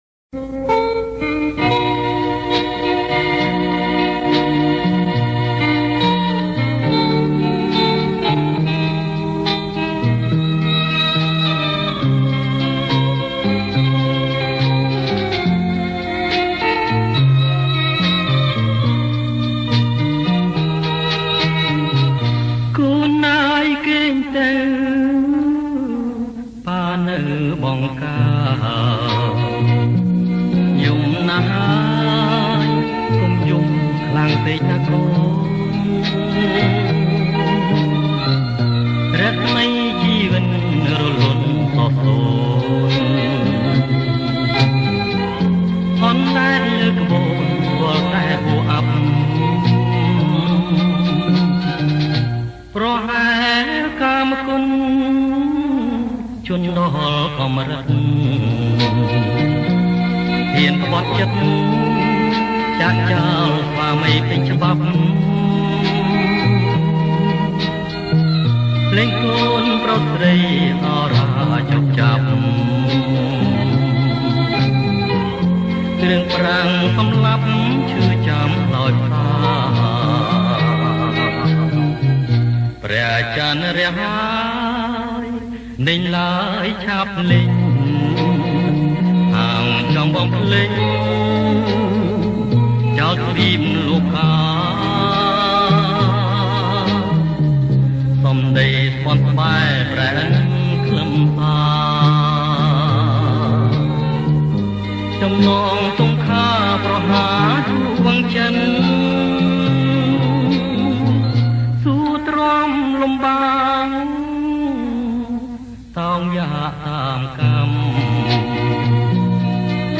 • ប្រគំជាចង្វាក់ Rumba Surf